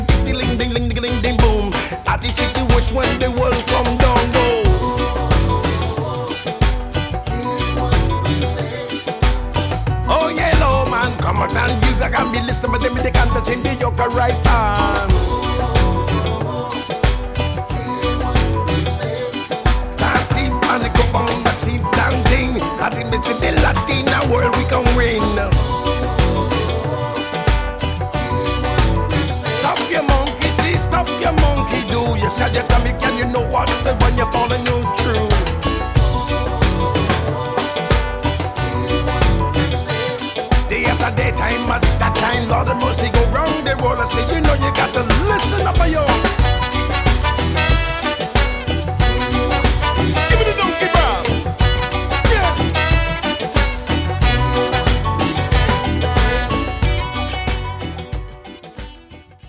LATIN TROPICAL SOUL EXPERIENCE